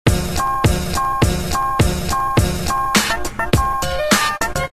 Рингтоны на СМС